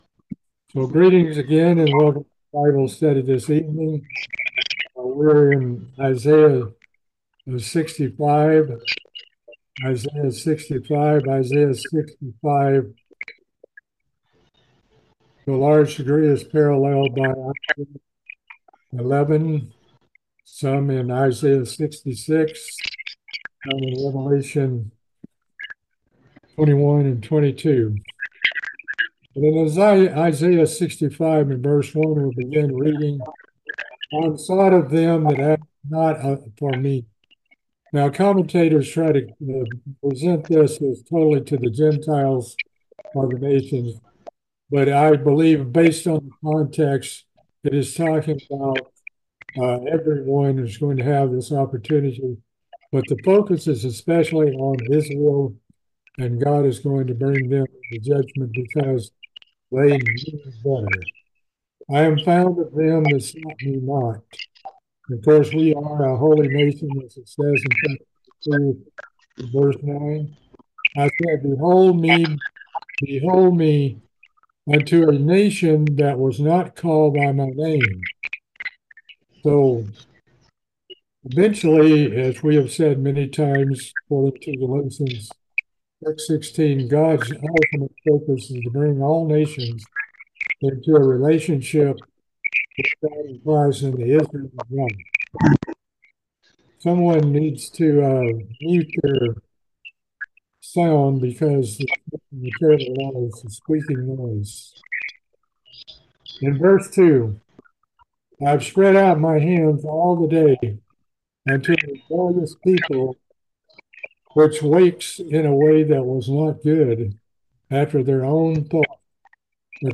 The final bible study of the book of Isaiah. We will be cover the last two chapter, 65 and 66.